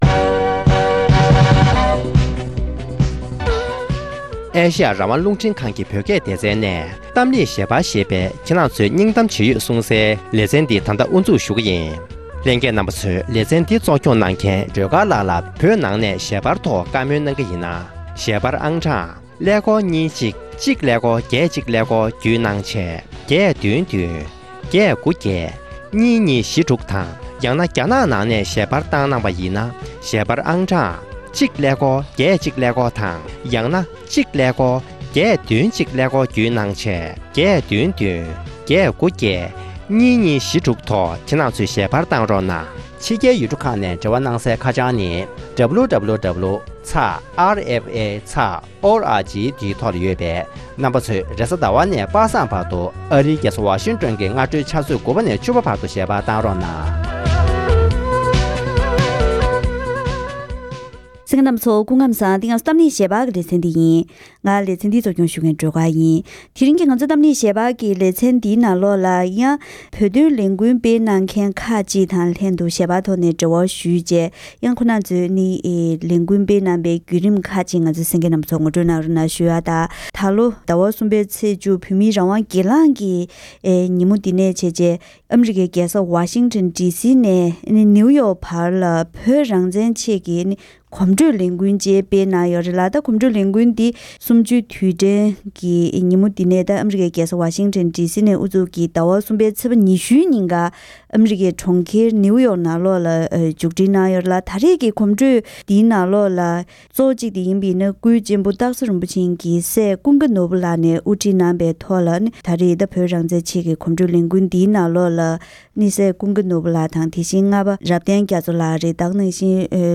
ཨ་རི་དང་ཡུ་རོབ། རྒྱ་གར་བཅས་ས་གནས་སུ་བོད་མི་སྒེར་ངོས་ནས་བོད་དོན་ལས་འགུལ་སྤེལ་བའི་ནང་ནས་ལས་འགུལ་སྤེལ་མཁན་སྒེར་སོ་སོར་གླེང་མོལ་གྱི་དམིགས་བསལ་ལས་རིམ།